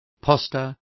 Complete with pronunciation of the translation of pasta.